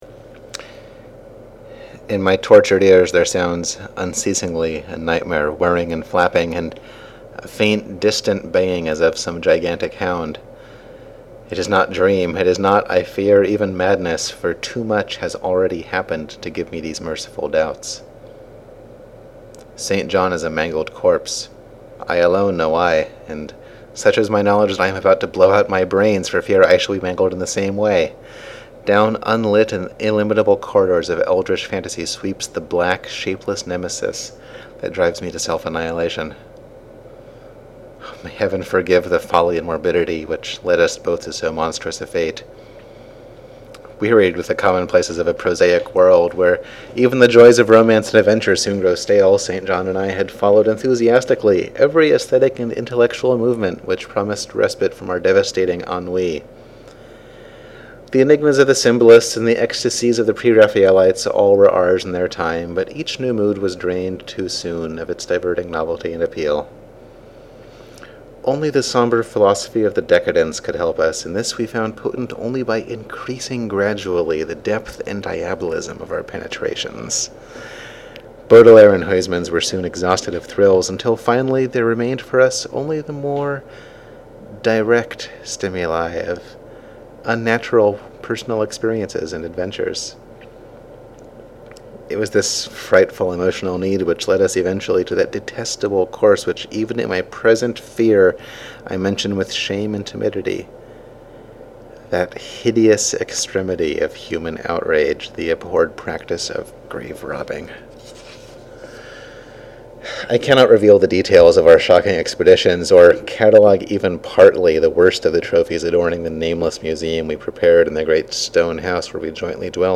INSTRUCTIONS: Make a recording of yourself reading a book you enjoy.